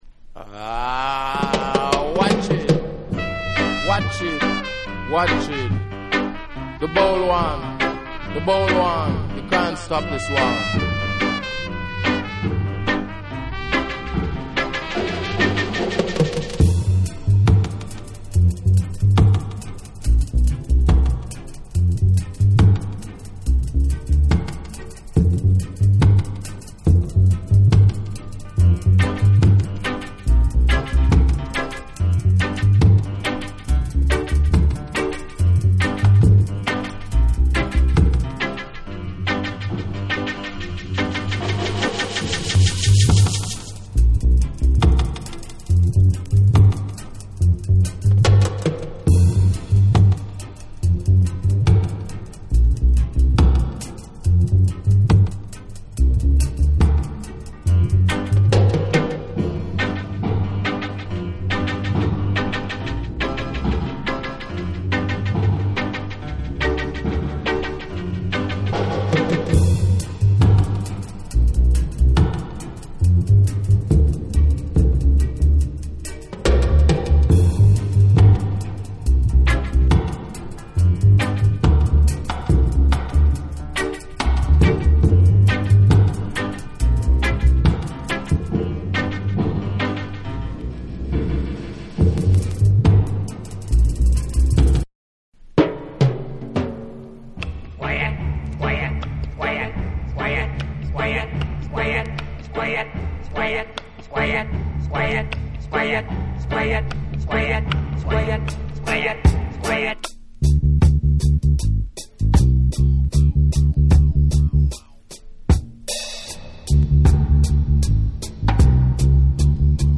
絶妙な卓捌きによって生み出された緊迫感溢れるダブ・サウンドを存分に楽しめる1枚です！
REGGAE & DUB